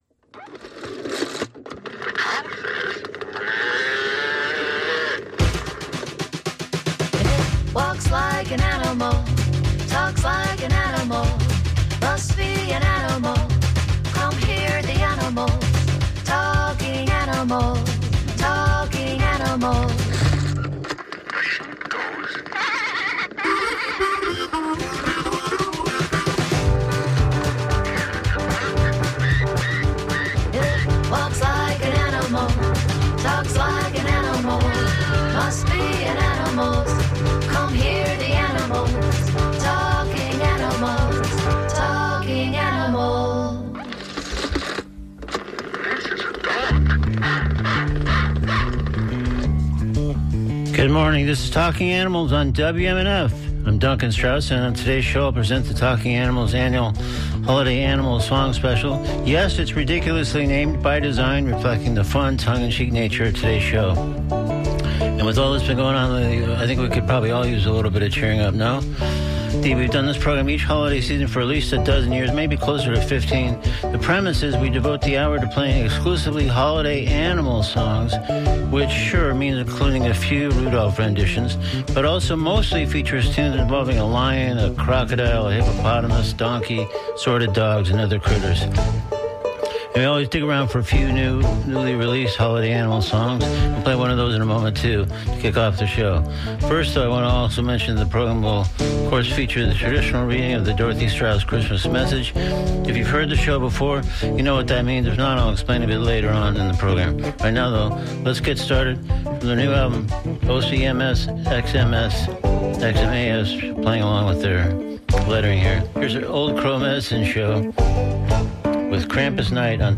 On the other hand, this year, I did not over-explain the premise (a show almost exclusively devoted to playing Holiday-oriented animal tunes), typically a fundamental component of these yearly programs. I did place a greater emphasis on playing new songs—or, at least, touting that I did so!